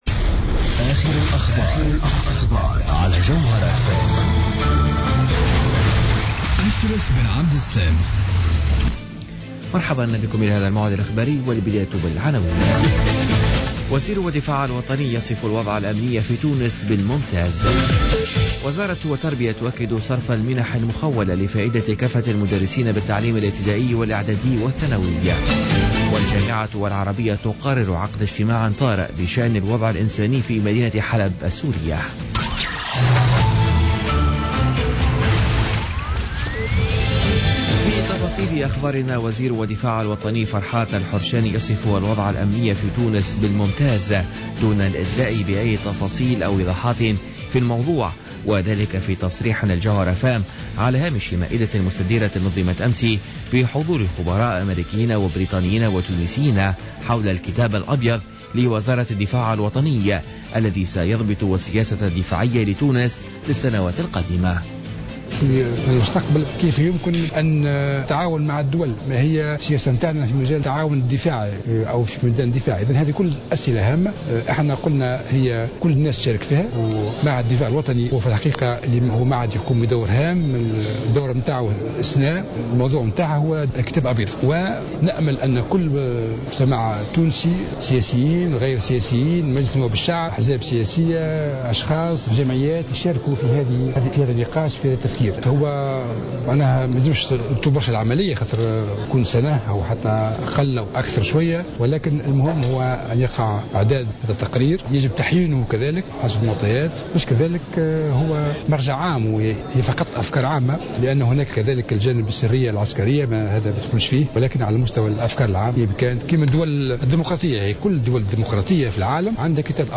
نشرة أخبار منتصف الليل ليوم الاربعاء 14 ديسمبر 2016